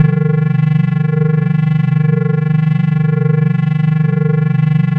spaceEngine_003.ogg